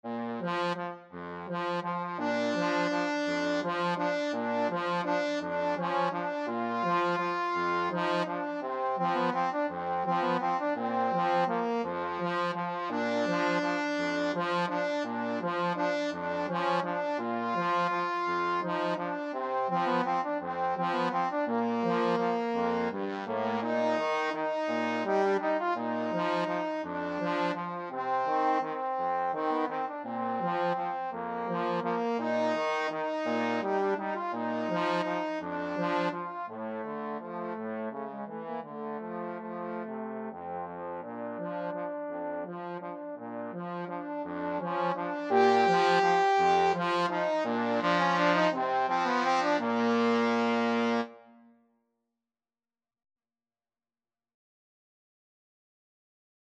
3/4 (View more 3/4 Music)
Tempo di valse =168
Classical (View more Classical Trombone Duet Music)